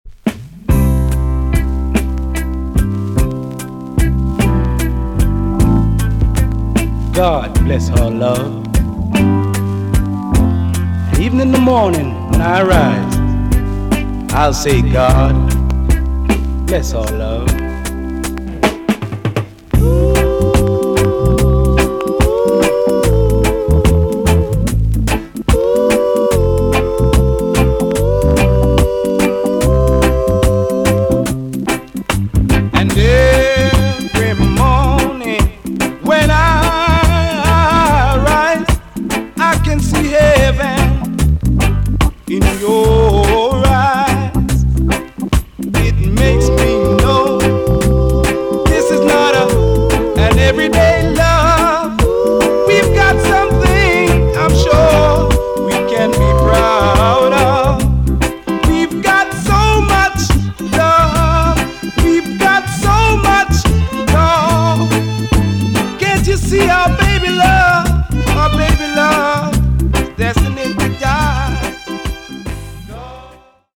TOP >REGGAE & ROOTS
EX-~VG+ 少し軽いチリノイズがありますが良好です。
1974 , NICE VOCAL TUNE!!